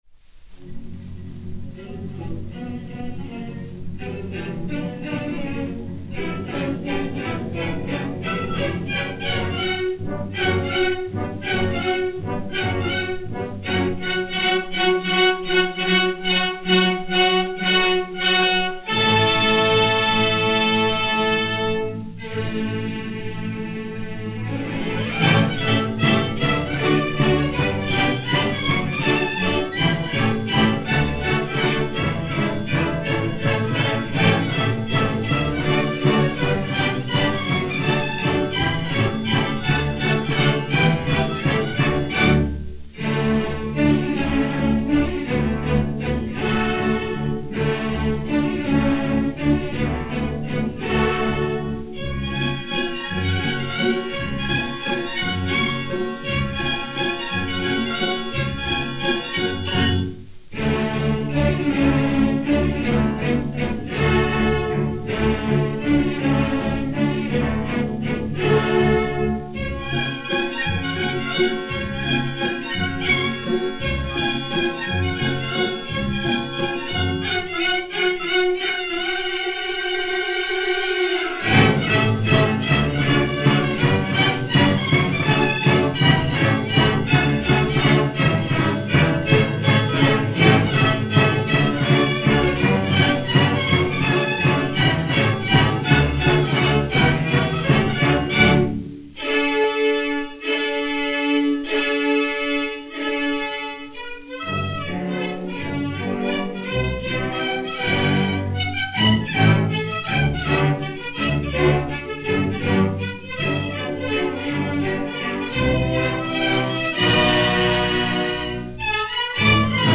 Orchestre Symphonique dir. Philippe Gaubert